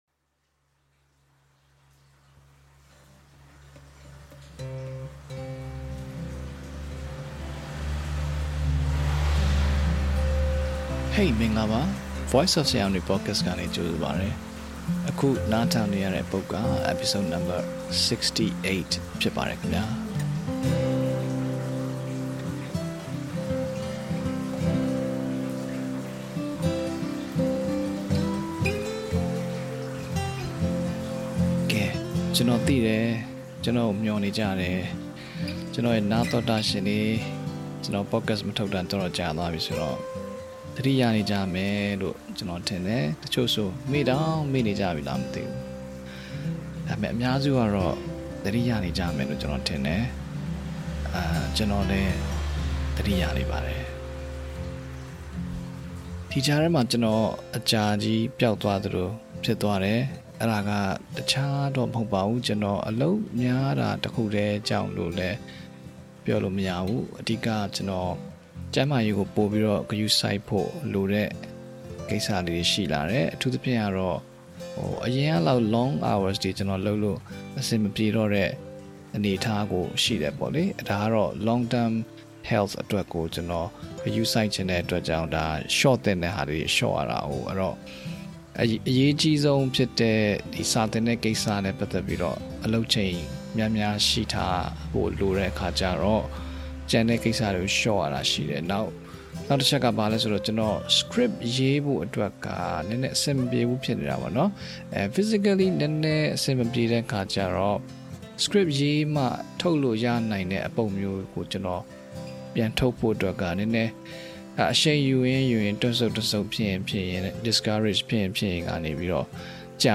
#65In this live round table talk with his students just before the end of 2023, the host answers questions that are related to language learning, communication skill, mindset, and life. At the end of this friendly and long conversation, there is a tip that will help you win more bits of your life in the new year 2024.